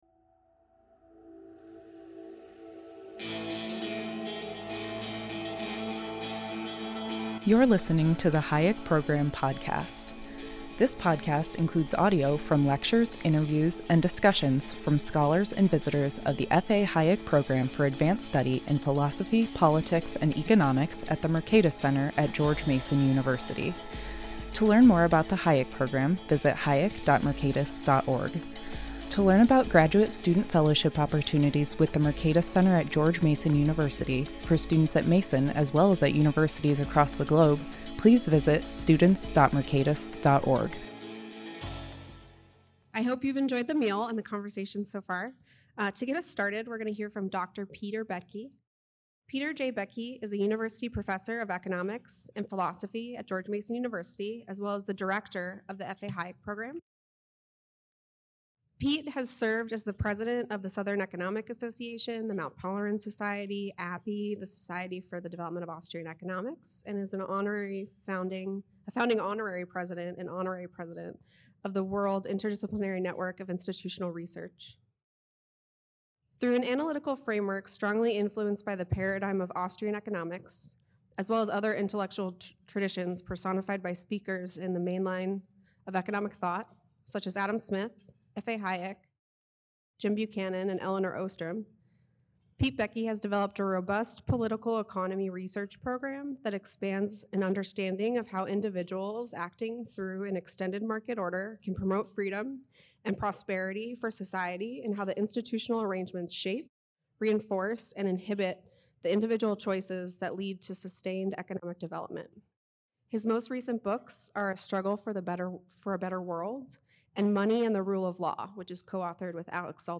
The first four episodes of this series will focus on The Legacy of Robert Higgs (Mercatus Center, 2024) and will feature a collection of short interviews with many of the chapter authors.